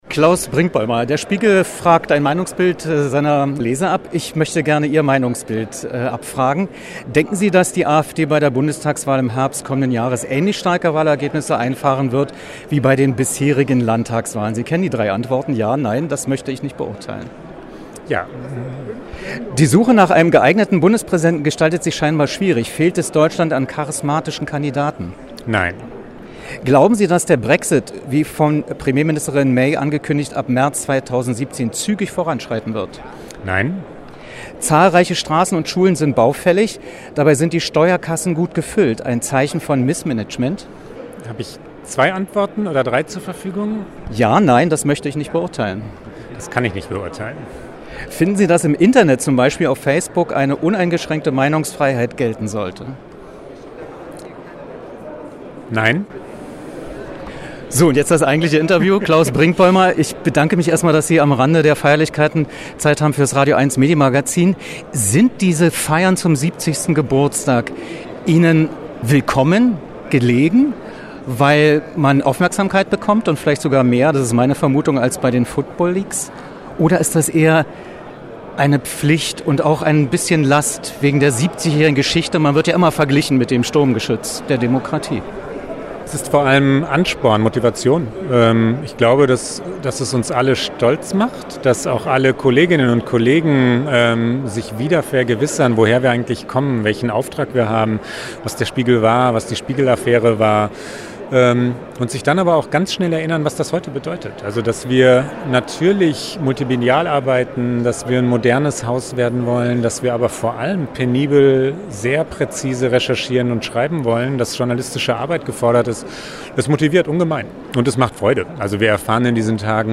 Wer: Klaus Brinkbäumer, Chefredakteur „Der Spiegel“, Herausgeber „Spiegel Online“, seit 2015
Was: Interview am Rande des Senatsempfangs „70 Jahre Spiegel“
Wo: Hamburg, Rathaus